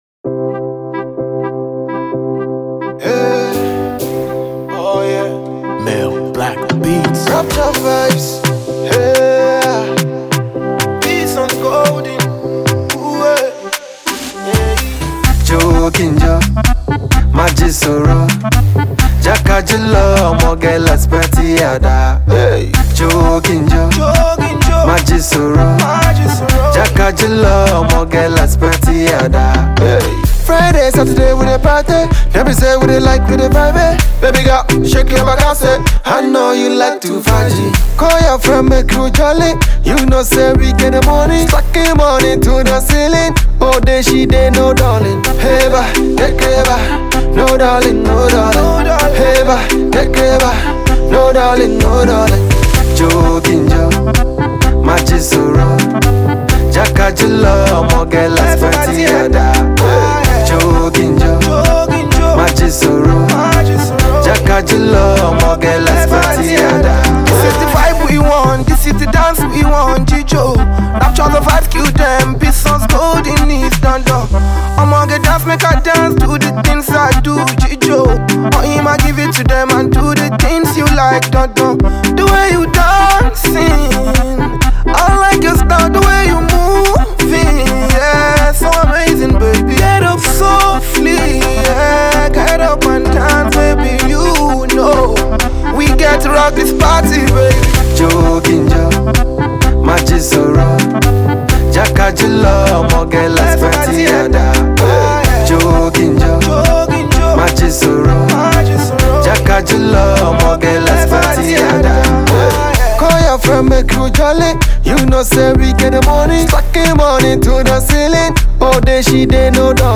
catchy beats and upbeat tempo